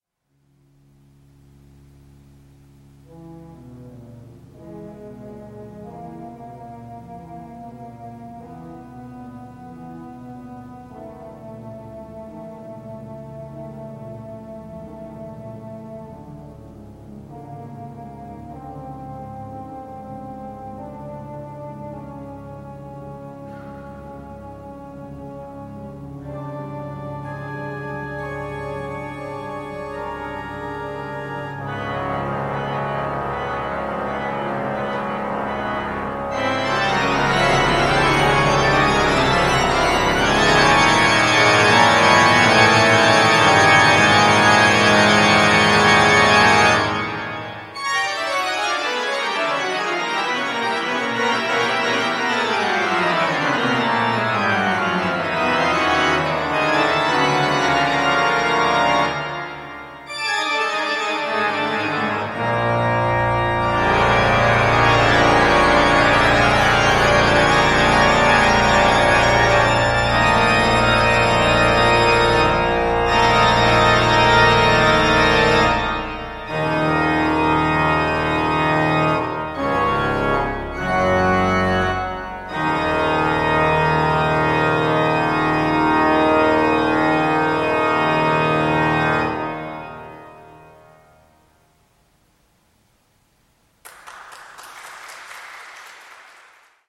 Aedian Skinner-Orgel der Riverside Church, New York